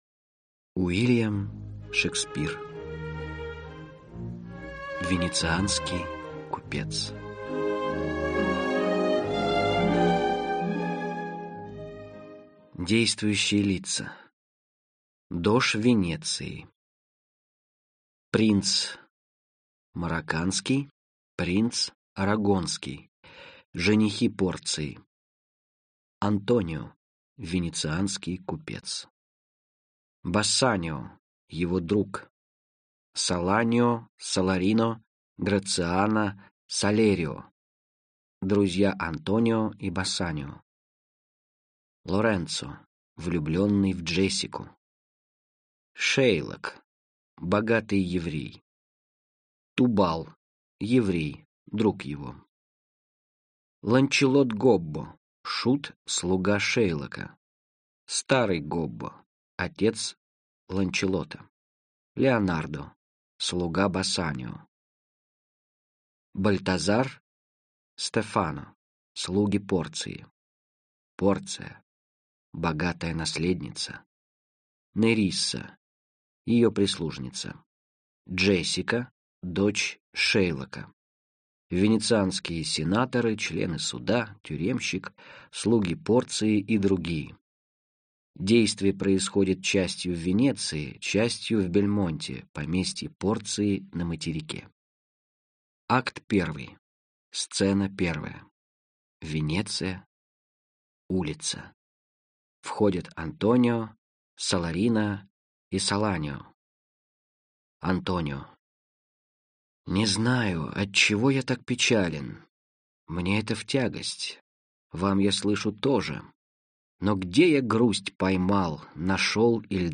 Аудиокнига Комедии и трагедии | Библиотека аудиокниг
Aудиокнига Комедии и трагедии Автор Уильям Шекспир